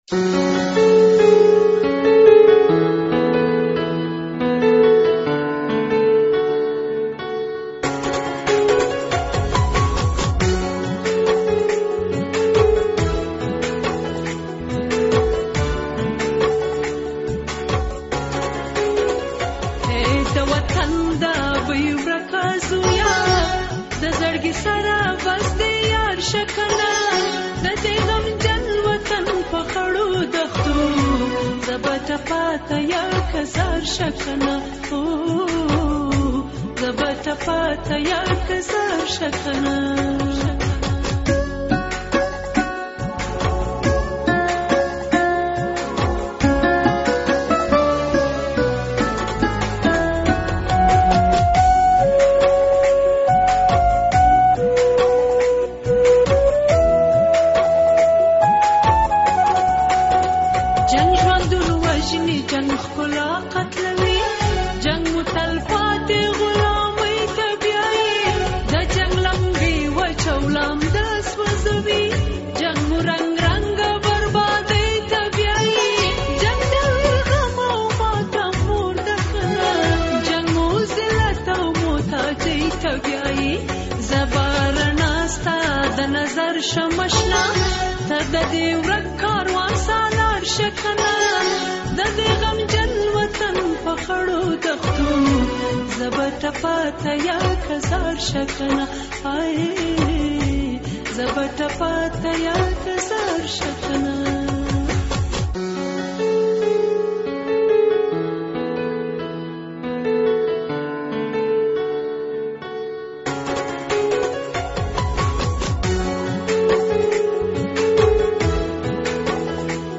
نوې ملي سندره